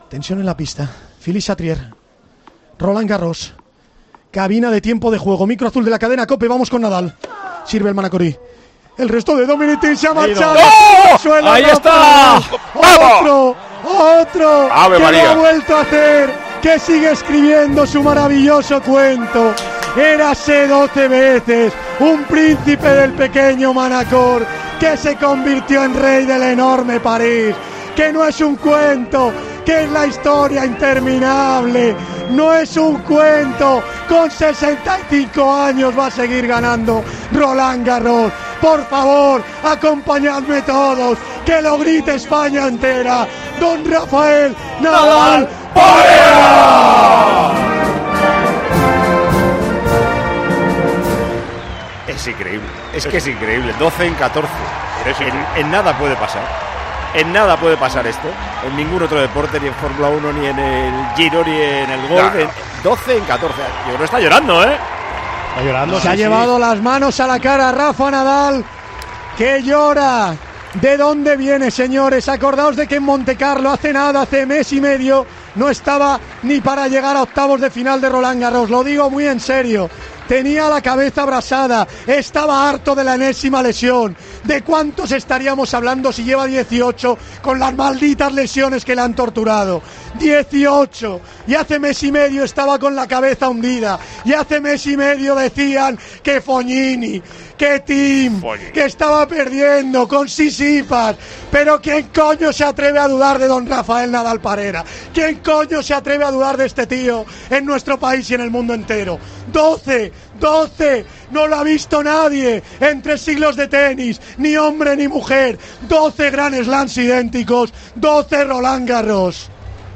Así narró Tiempo de Juego el último punto con el que Nadal ganaba su 12ª Roland Garros